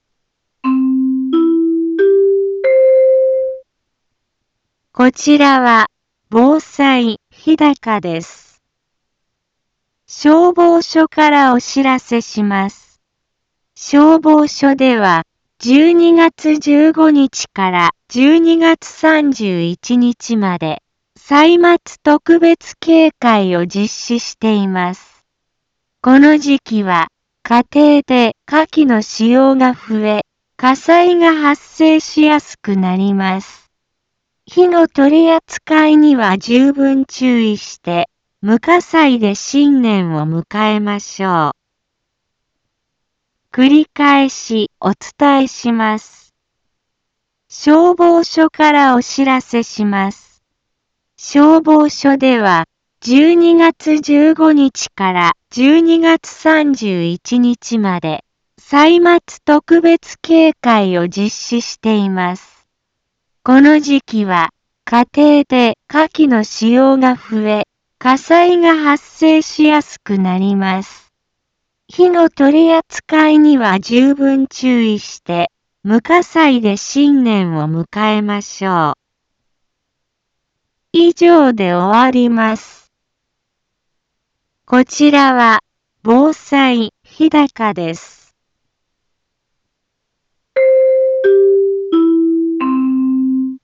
一般放送情報
Back Home 一般放送情報 音声放送 再生 一般放送情報 登録日時：2020-12-15 10:03:20 タイトル：歳末特別警戒 インフォメーション：こちらは、防災日高です。